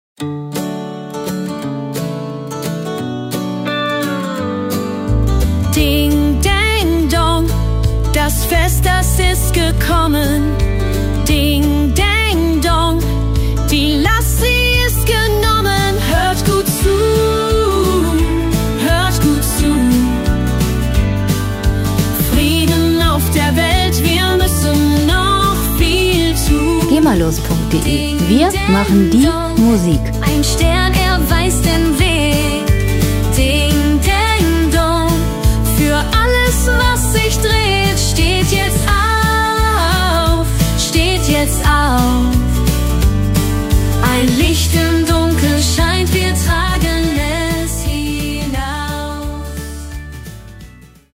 Gemafreie moderne Weihnachtslieder
Musikstil: Country Pop
Tempo: 87 bpm
Tonart: D-Dur/E-Dur
Charakter: freundlich, liebenswert